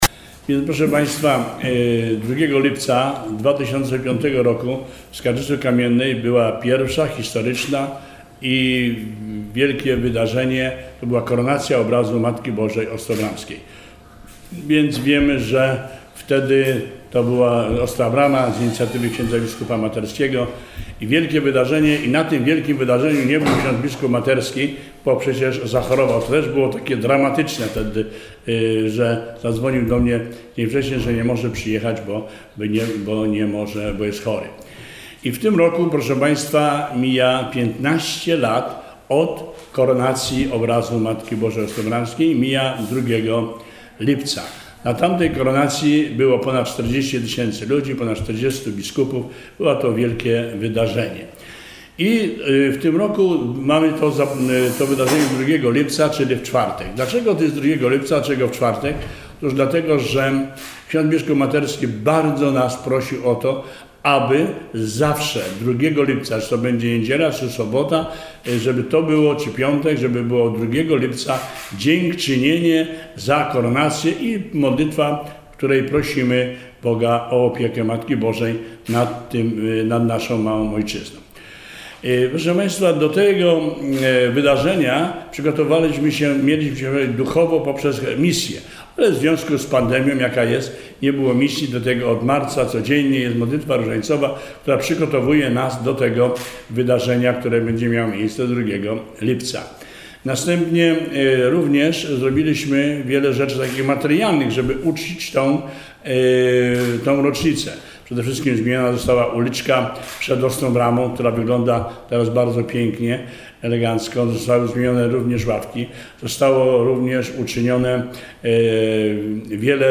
W środę 24 czerwca, w Centrum Ostra Brama, odbyła się konferencja prasowa poświęcona 15. rocznicy koronacji obrazu Matki Bożej Ostrobramskiej.